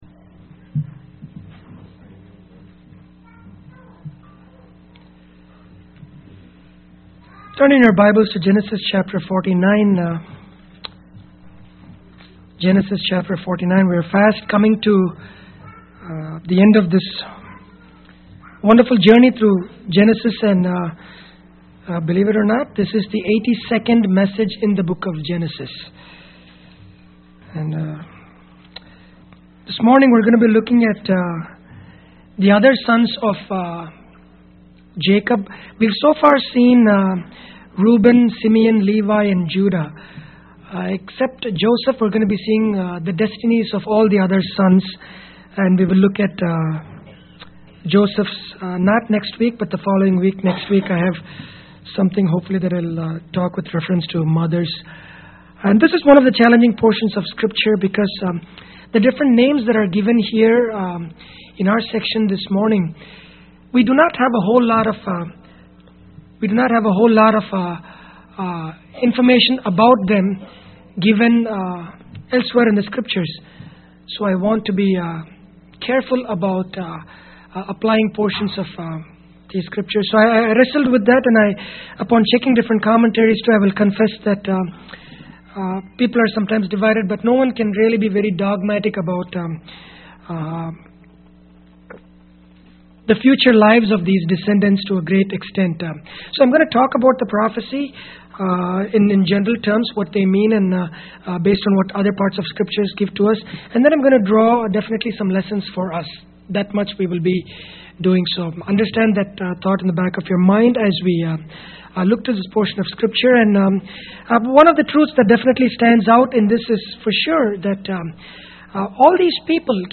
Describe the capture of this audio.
Grace Bible Church of Windsor » Actions Shape Our Destinies